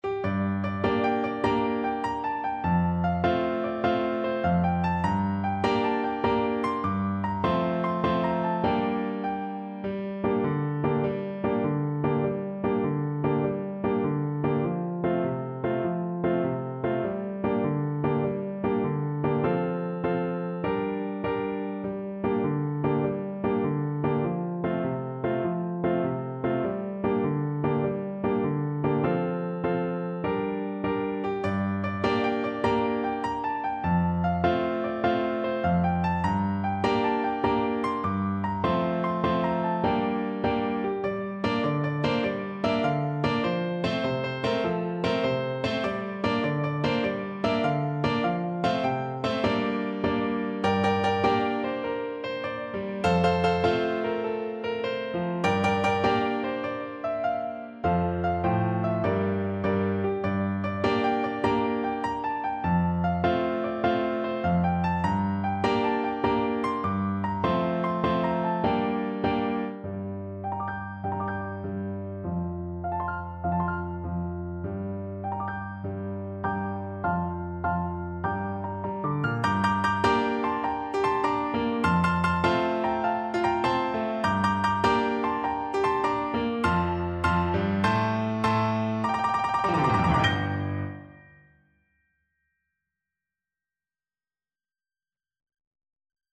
Allegro .=c.100 (View more music marked Allegro)
6/8 (View more 6/8 Music)